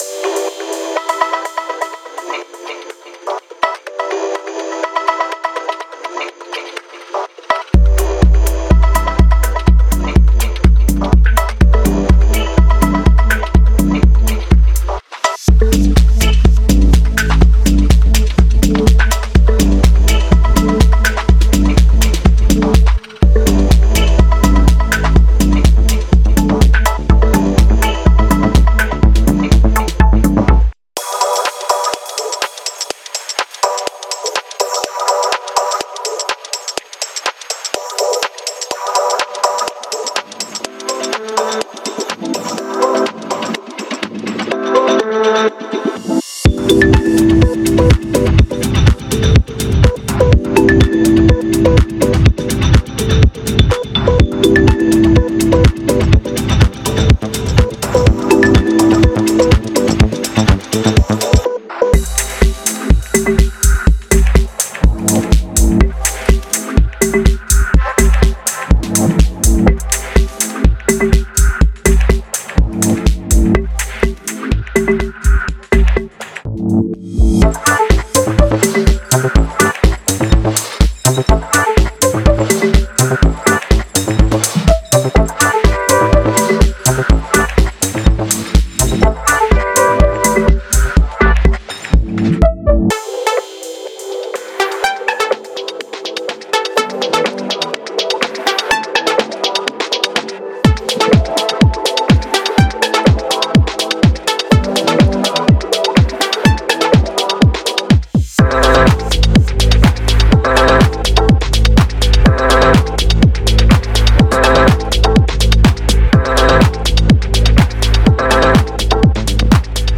Genre:House